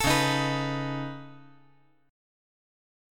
Db7sus2#5 Chord
Listen to Db7sus2#5 strummed